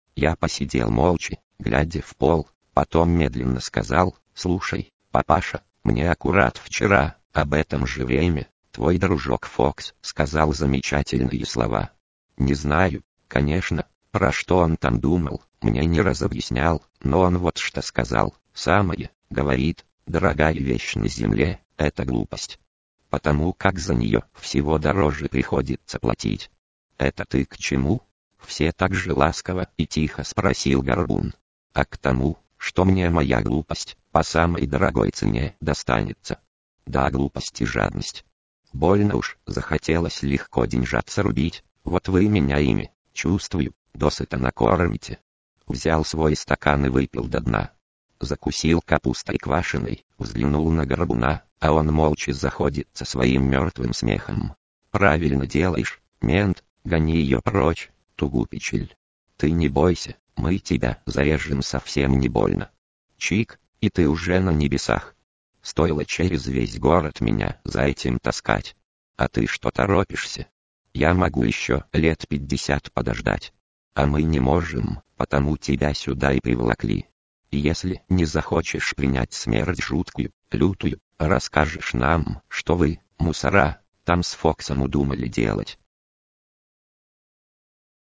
Сегодня мы предлагаем озвучку текста синтезатором человеческой речи Дигало (Николай).